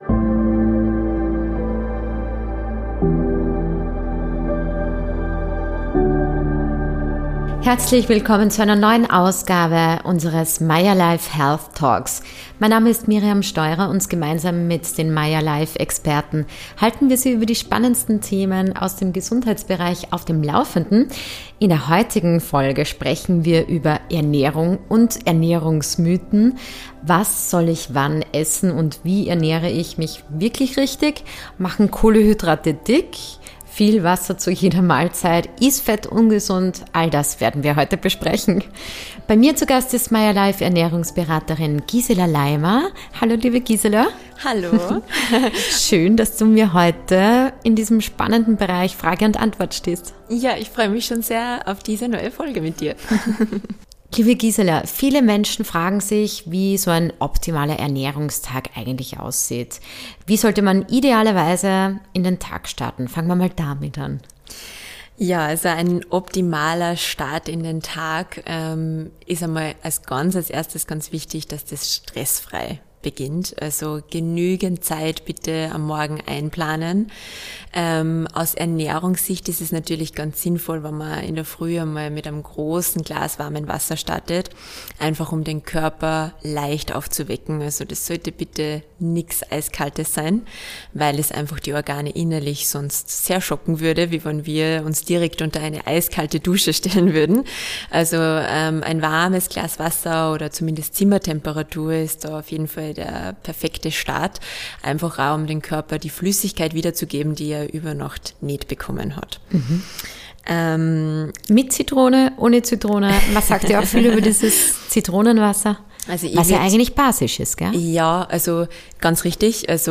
Erfahren Sie mehr über konkrete Alltagstipps, den Umgang mit Ausnahmen, die Rolle von Rhythmus und Sättigung – und warum bewusste Ernährung mehr ist als der richtige Speiseplan. Ein Gespräch über Orientierung, praktische Umsetzung und die kleinen Entscheidungen mit großer Wirkung.